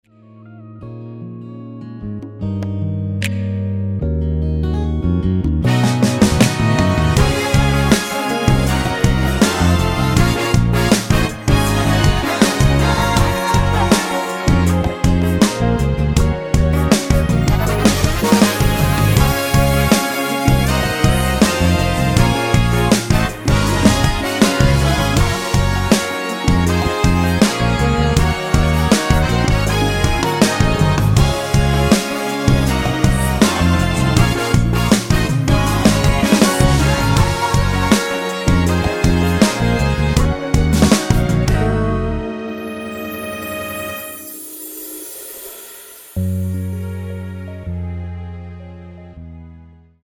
처음 시작 보컬 부분은 코러스가 아니기 때문에 직접 노래를 하셔야 됩니다.
원키 코러스 포함된 MR 입니다.(미리듣기 참조)
Db
앞부분30초, 뒷부분30초씩 편집해서 올려 드리고 있습니다.